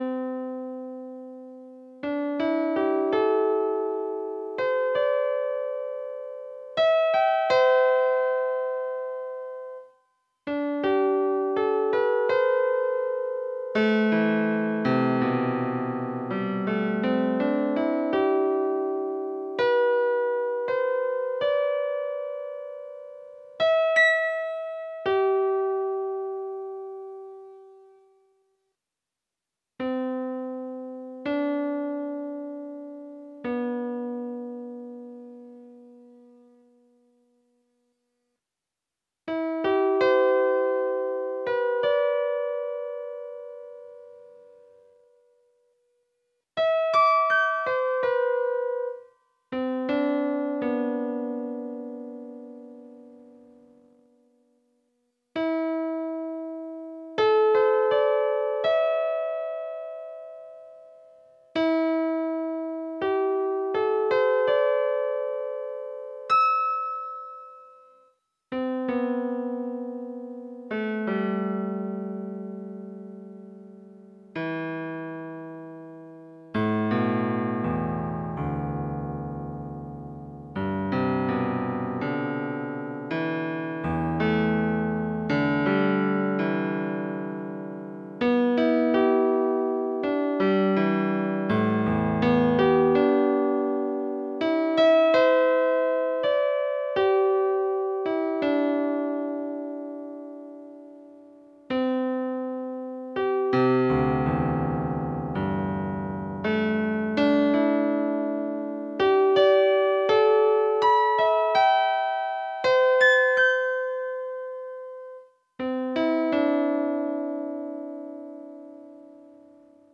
Pour cette année 2025, j’ai décidé de retourner voir ce vénérable châtaignier et d’ enregistrer dans la nuit sa superbe mélodie , que vous pouvez écouter ci-dessous.
En fait, ne pouvant atteindre les feuilles trop hautes, j’ai connecté mon boitier Bamboo M à un jeune châtaignier non lui de l’Ancien… surement connectés entre eux !